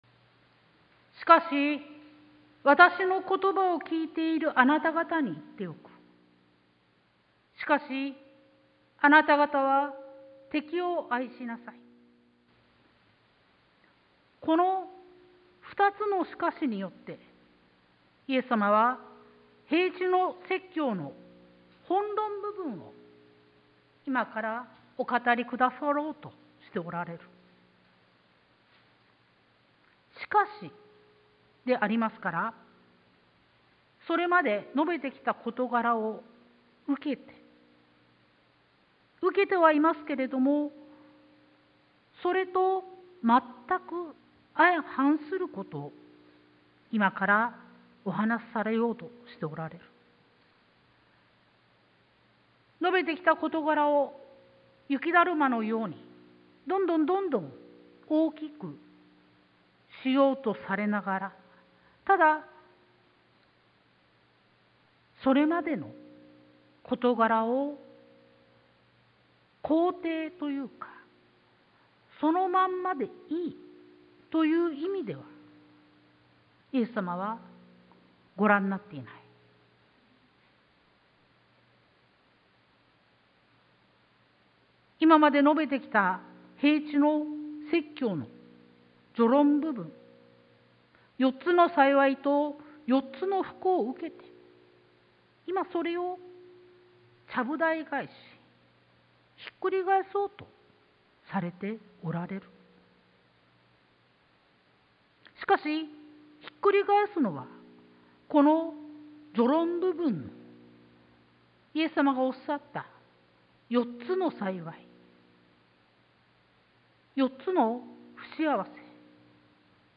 sermon-2022-09-11